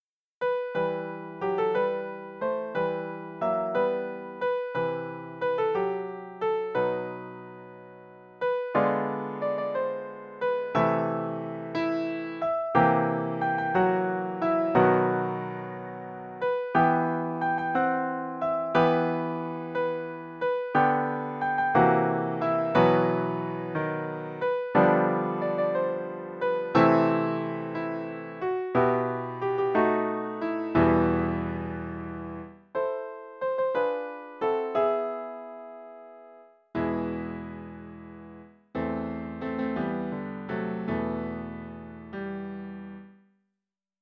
028-Ég bið þig um blessun þína (piano)